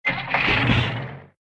KART_Engine_start_2.ogg